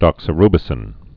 (dŏksə-rbĭ-sĭn)